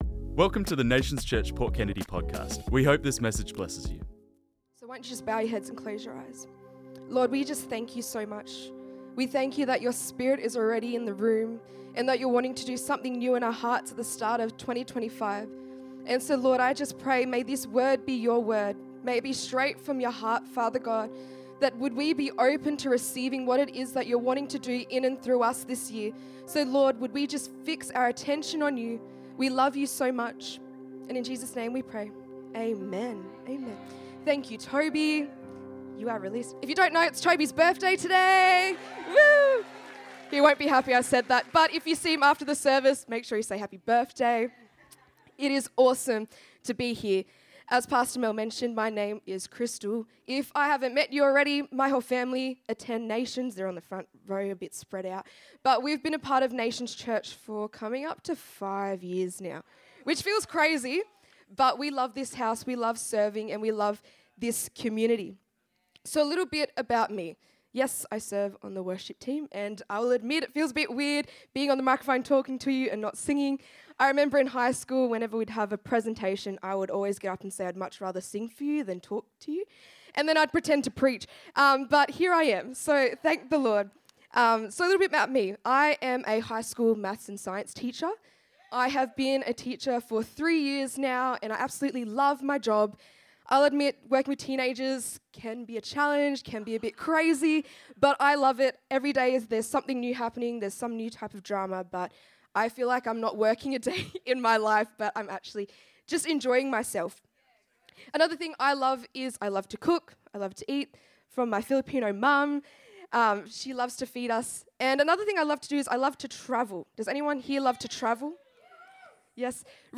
This message was preached on Sunday 19th January 2025